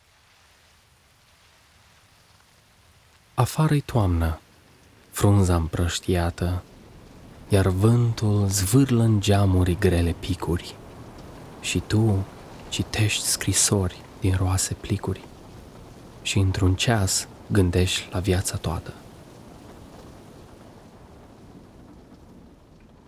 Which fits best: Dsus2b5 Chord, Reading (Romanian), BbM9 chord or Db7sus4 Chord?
Reading (Romanian)